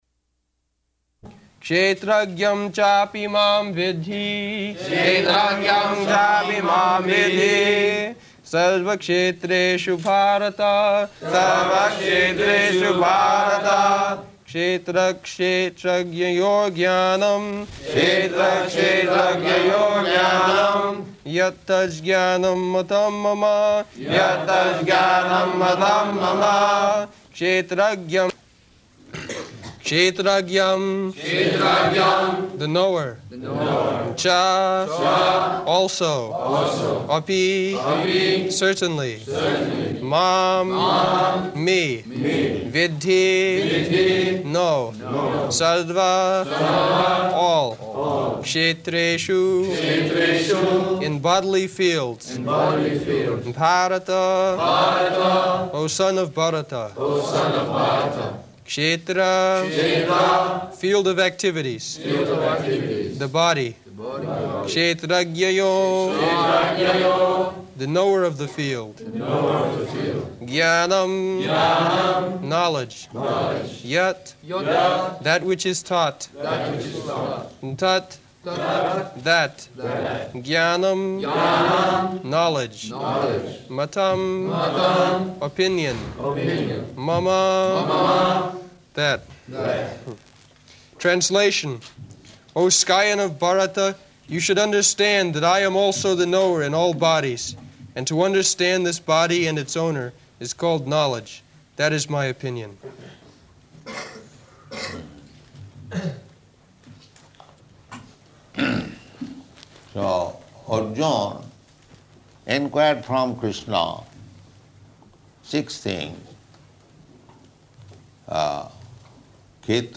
Location: Paris
[Prabhupāda and devotees repeat]
[leads chanting of synonyms]